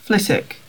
Flitwick (/ˈflɪtɪk/